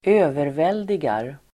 Uttal: [²'ö:vervel:digar]